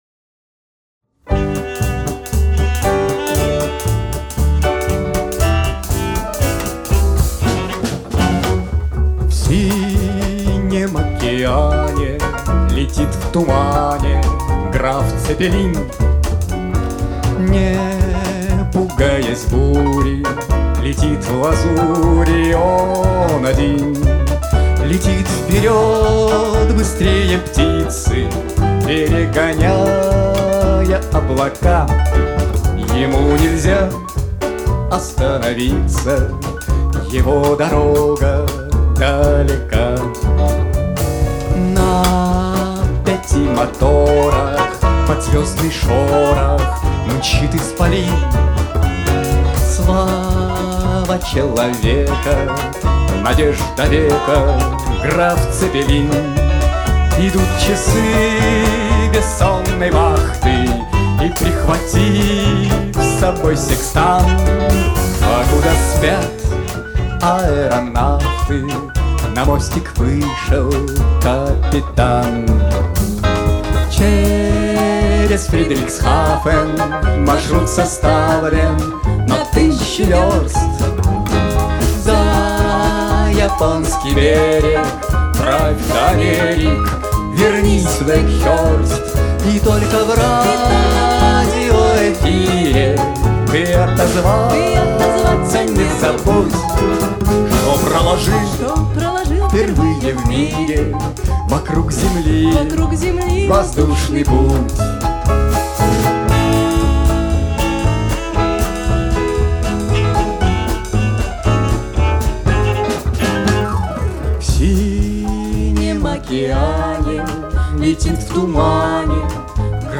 Новогодний концерт 27.12.2019
ударные
контрабас, бас-гитара
виолончель, вокал
клавишные, вокал
вокал и гитара